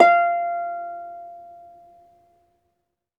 HARP GN5 SUS.wav